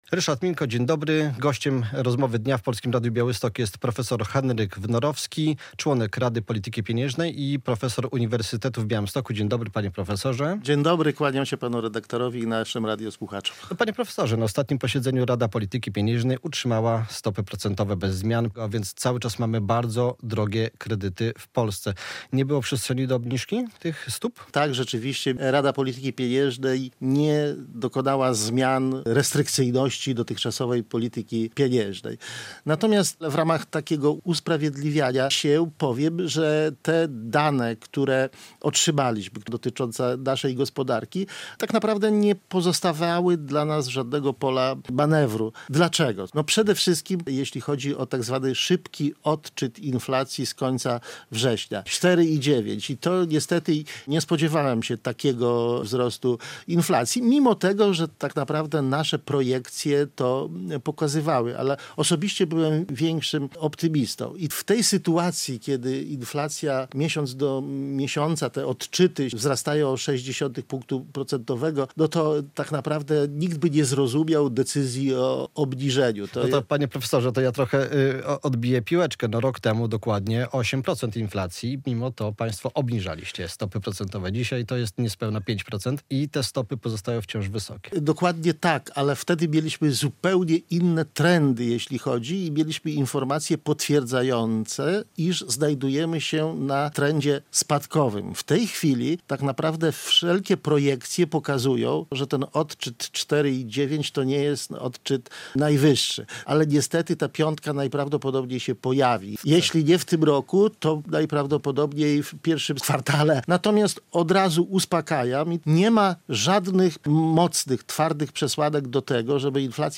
W Rozmowie Dnia w Polskim Radiu Białystok prof. Wnorowski wyjaśniał, że tym razem RPP była wyjątkowo zgodna, by przez kolejne miesiące utrzymać referencyjną stopę NPB na poziomie 5,75 proc.